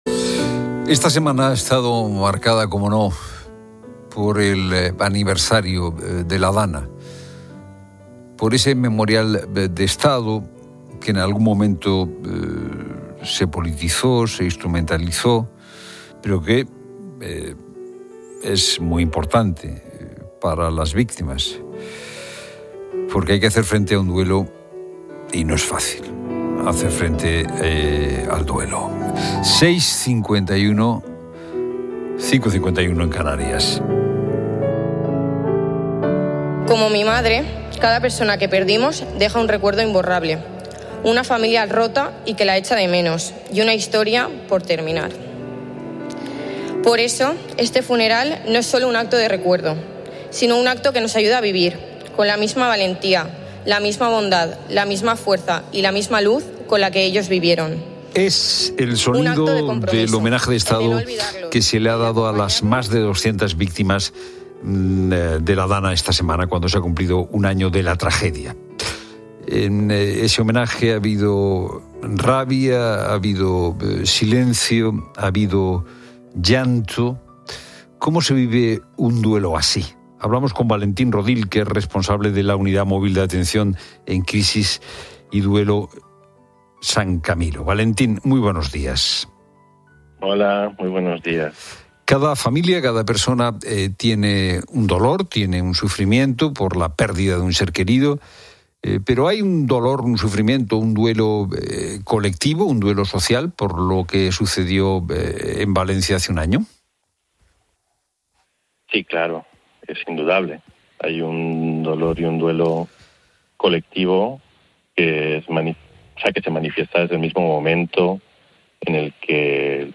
entrevista sobre el duelo